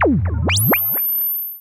Index of /musicradar/sci-fi-samples/Theremin
Theremin_FX_15.wav